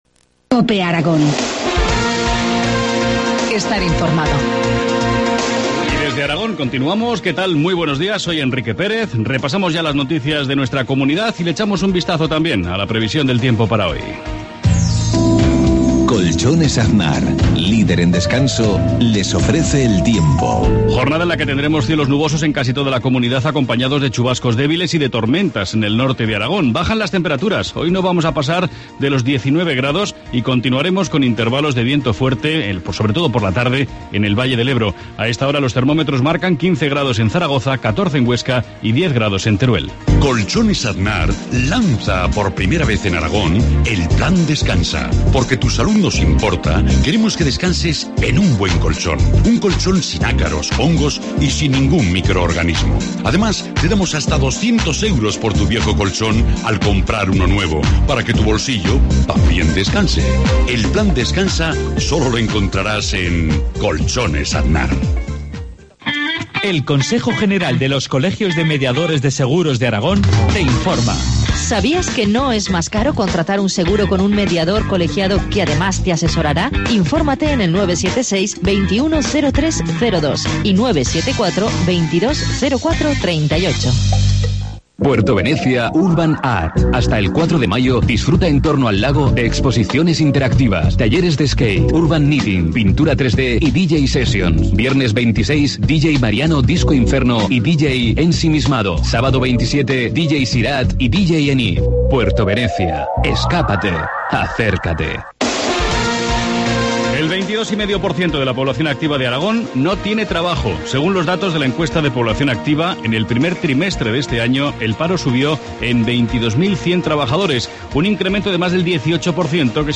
Informativo matinal, viernes 26 de abril, 7.53 horas